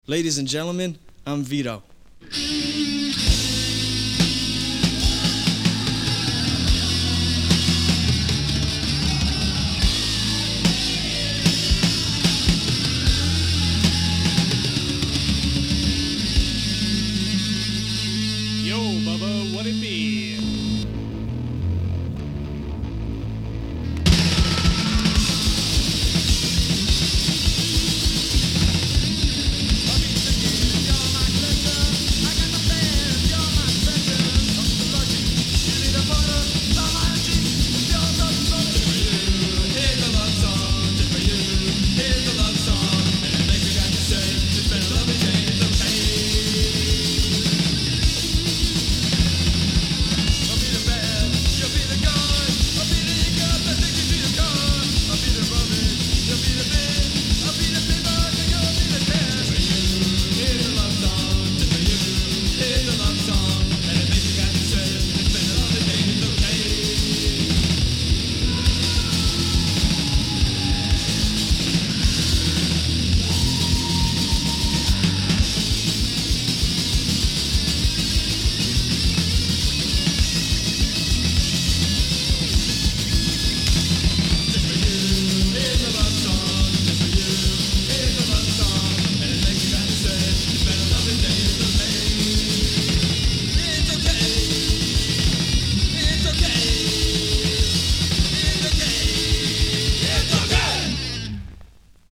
Genre: Hardcore Punk